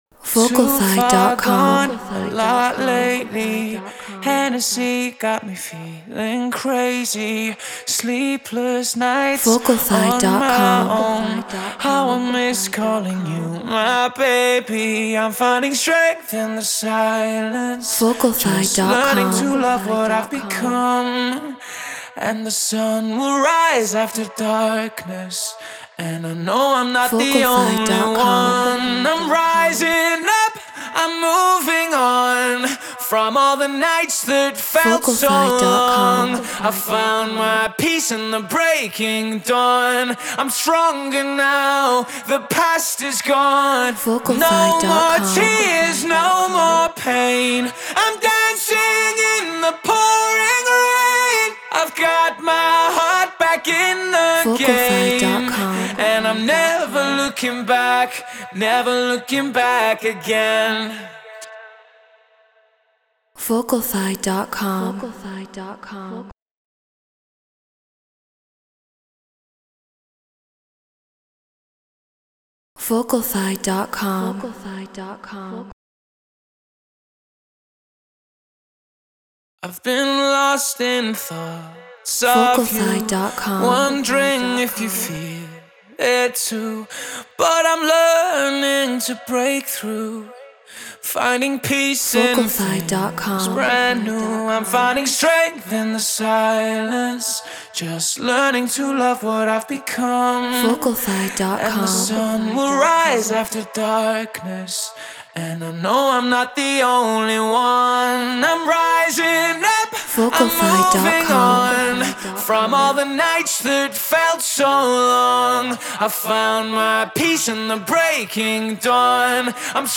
Progressive House 127 BPM Amaj
Neumann TLM 103 Apollo Twin X Pro Tools Treated Room